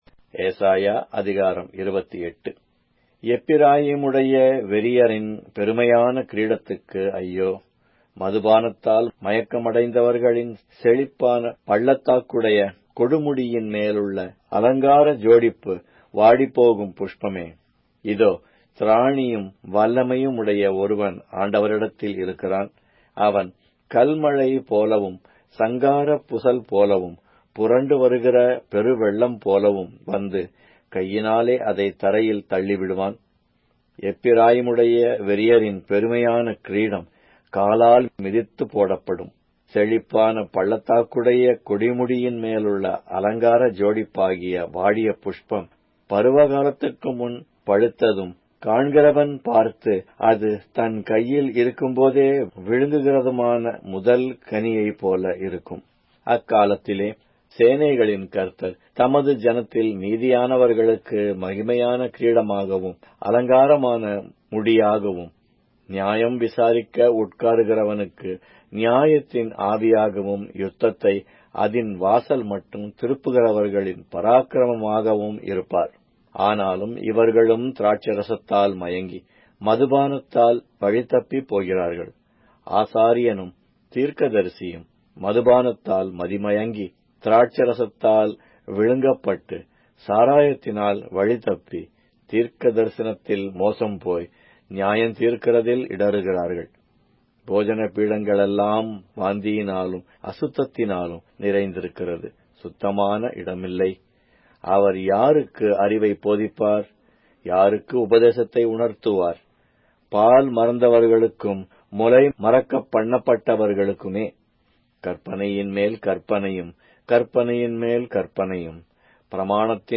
Tamil Audio Bible - Isaiah 48 in Ervhi bible version